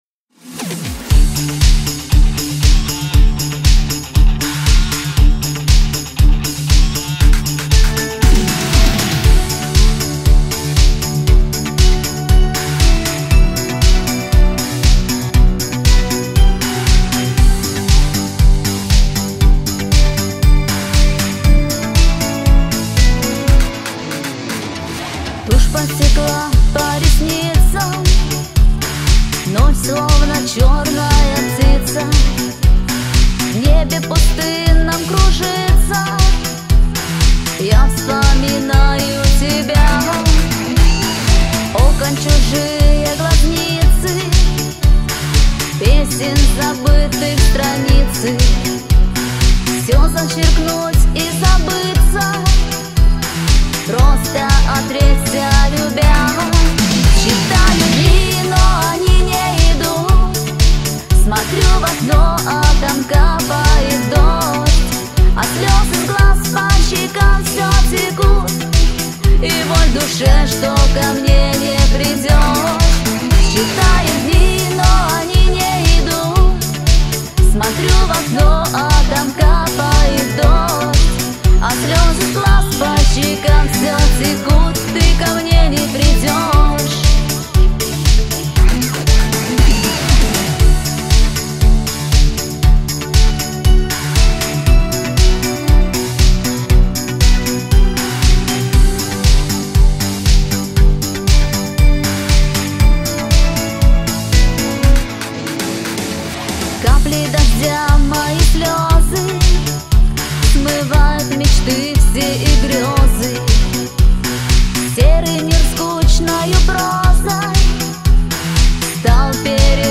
Узбекские песни
• Качество: 320 kbps, Stereo